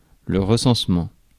Ääntäminen
Ääntäminen France: IPA: /ʁə.sɑ̃s.mɑ̃/ Haettu sana löytyi näillä lähdekielillä: ranska Käännös 1. преброяване на населението {n} Suku: m .